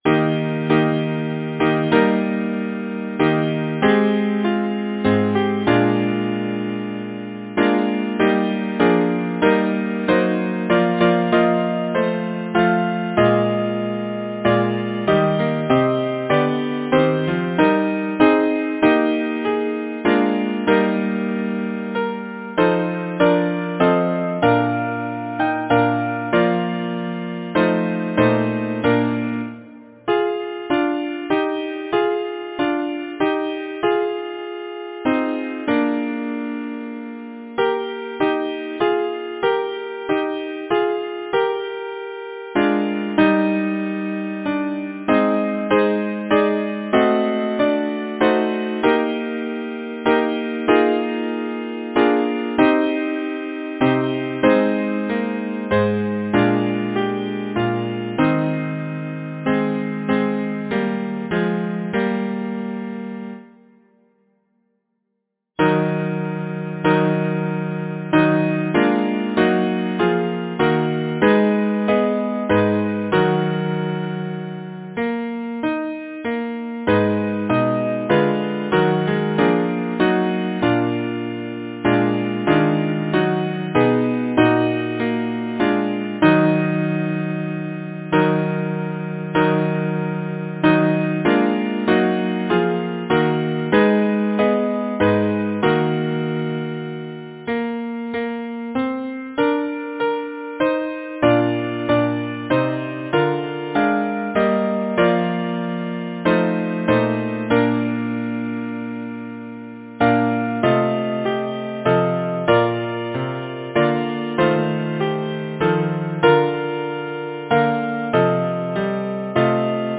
Title: Snowflakes Composer: Frederick St. John Lacy Lyricist: Henry Wadsworth Longfellow Number of voices: 4vv Voicing: SATB Genre: Secular, Partsong
Language: English Instruments: A cappella